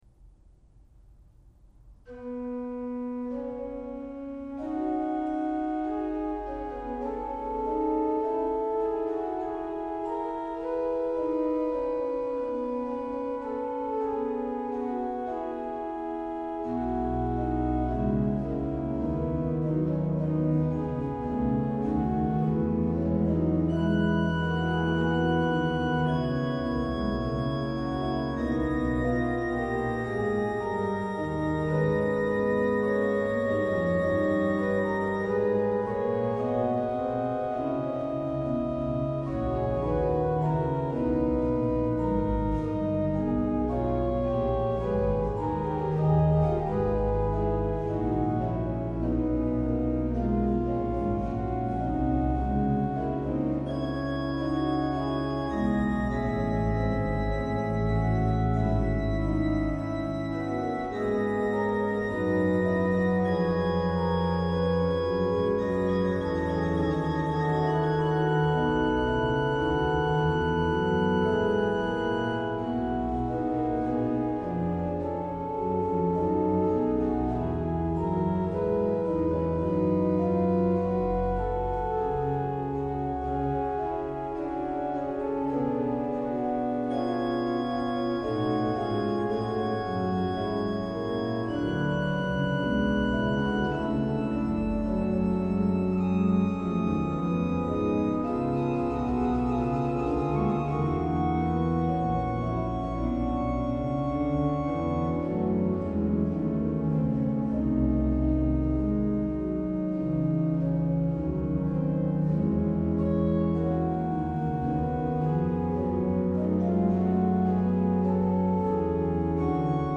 Quello grande, sempre con la pedaliera, usa il tema come cantus firmus, mentre il 'piccolo' si limita a citarne l'incipit. Inoltre la polifonia del 'grande' elabora in forma fugata il medesimo tema, come mostra questo esempio.
organo